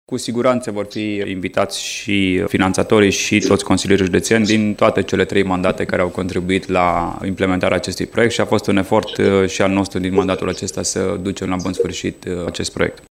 Vicepreședintele Consiliului Județean Timiș, Alexandru Iovescu, a transmis că se va ține cont de sugestie și că nu va fi ratat nimeni la recunoașterea contribuțiilor.